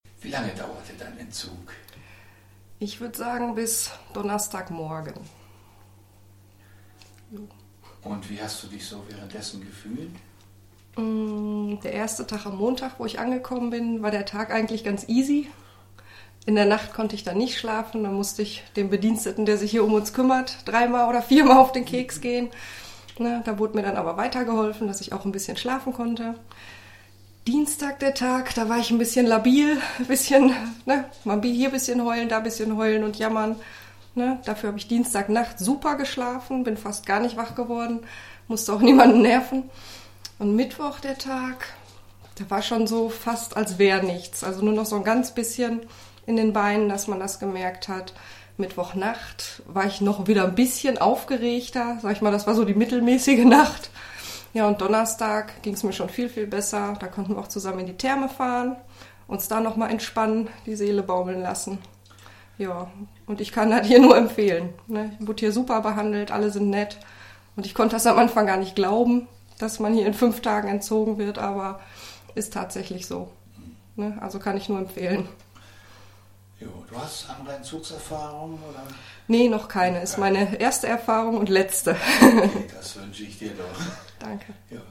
Das sagen Patienten aus Deutschland über das ESCAPE-Verfahren